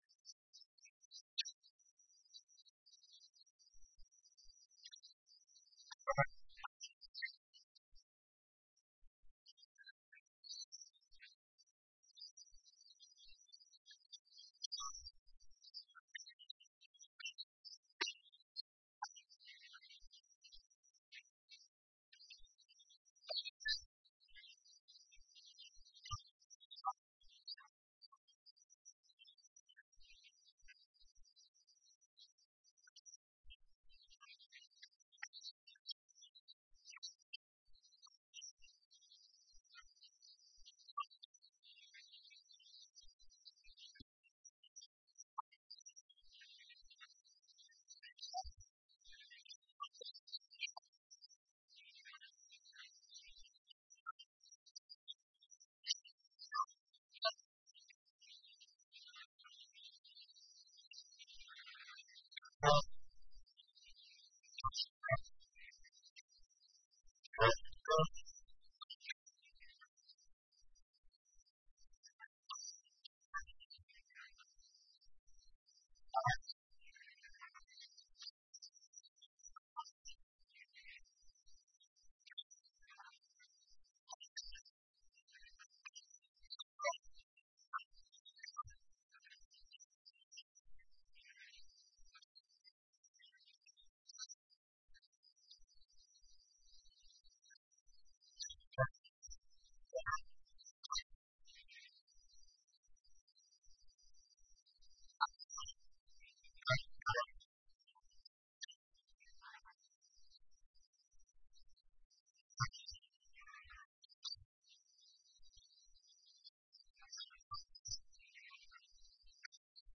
بیانات درمراسم اعطای سردوشی به فارغ‌التحصیلان دانشگاه علوم نظامی نیروی زمینی